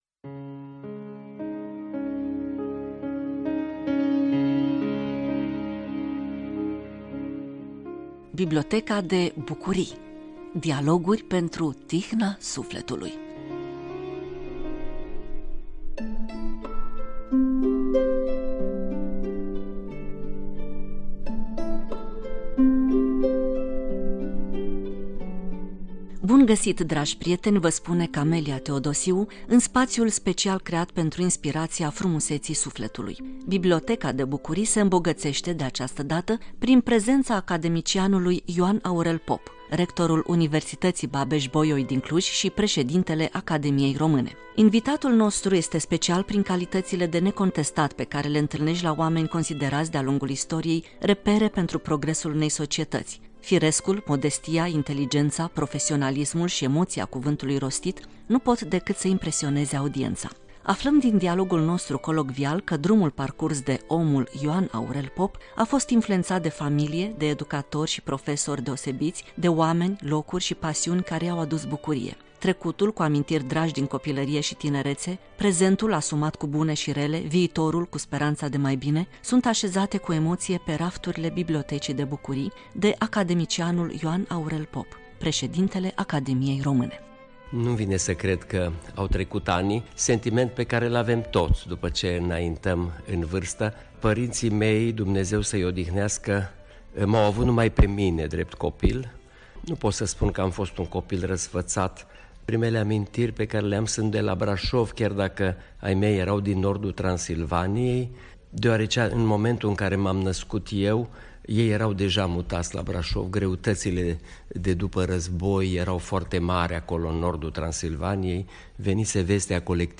Biblioteca de Bucurii – Dialoguri pentru tihna sufletului
Invitatul nostru este special prin calităţile de necontestat pe care le întâlneşti la oameni consideraţi, de-a lungul istoriei, repere pentru progresul unei societăţi – firescul, modestia, inteligenţa, profesionalismul şi emoţia cuvântului rostit nu pot decât să impresioneze audienţa.